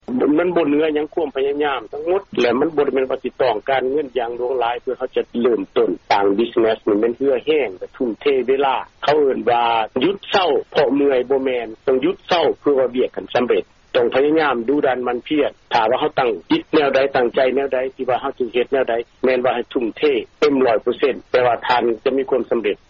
ທີ່ທ່ານຫາກໍໄດ້ຮັບຟັງຜ່ານໄປນັ້ນ ແມ່ນການໂອ້ລົມກັບລາວອາເມຣິກັນຄອບຄົວນຶ່ງ ໃນເຂດເມືອງໂທເລໂດ ລັດໂອຮາຍໂອ.